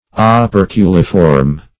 Search Result for " operculiform" : The Collaborative International Dictionary of English v.0.48: Operculiform \O*per"cu*li*form\, a. [L. operculum a cover + -form: cf. F. operculiforme.] Having the form of a lid or cover.